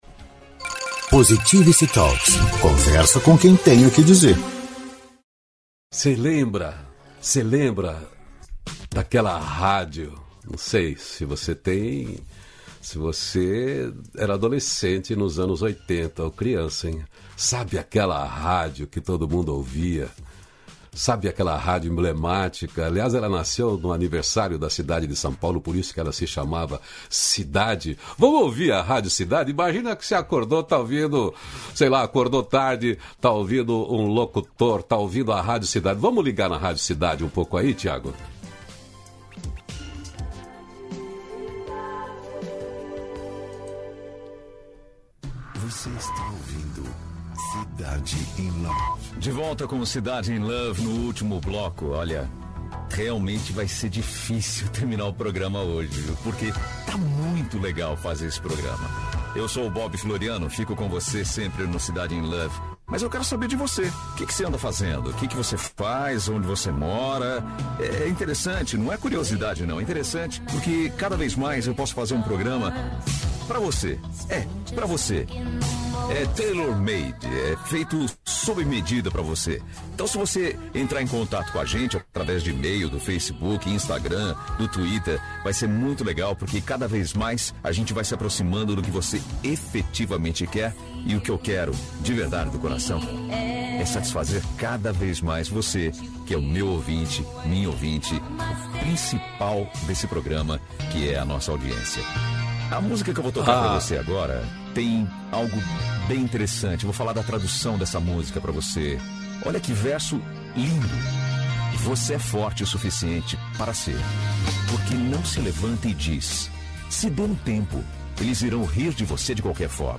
258-feliz-dia-novo-entrevista.mp3